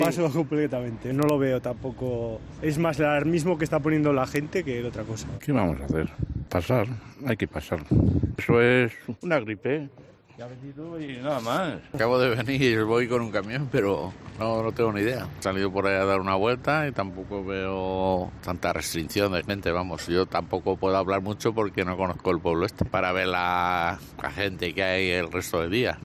La situación en Haro por el coronavirus contada por sus vecinos